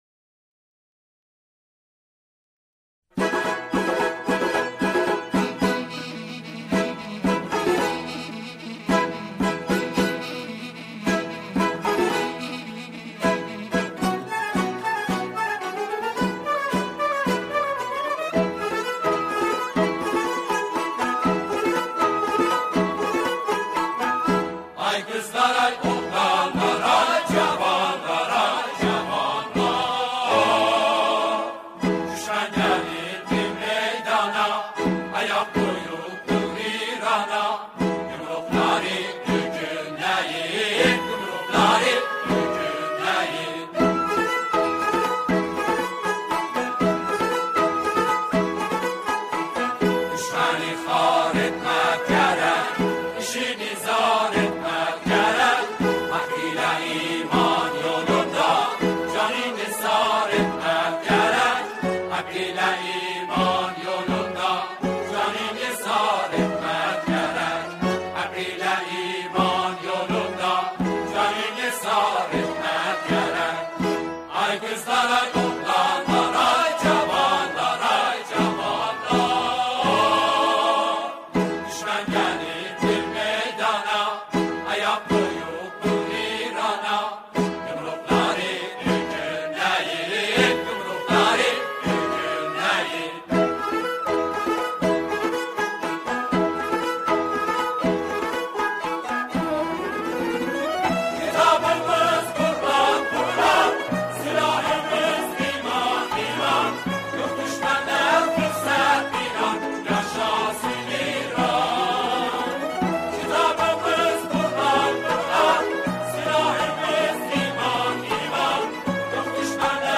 سرودهای دهه فجر
با گویش دلنشین آذری سروده و اجرا شده است